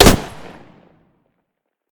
defender-shot-3.ogg